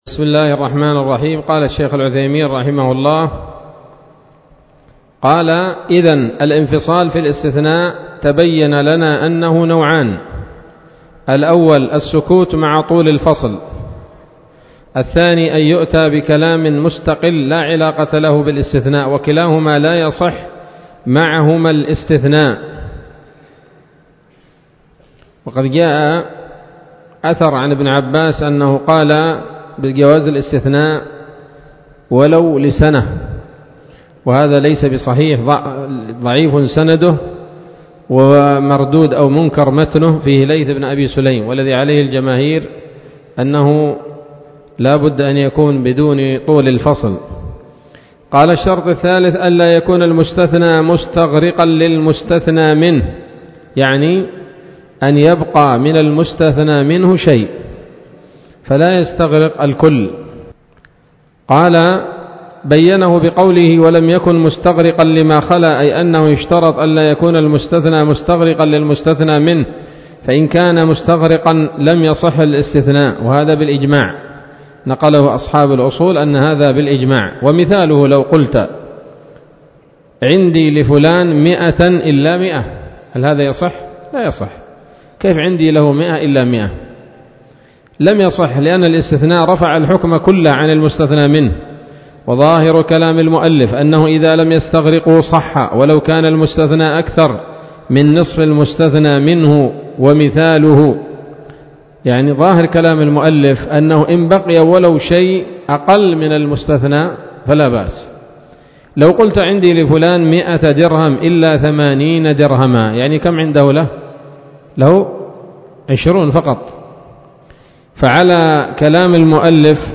الدرس الحادي والأربعون من شرح نظم الورقات للعلامة العثيمين رحمه الله تعالى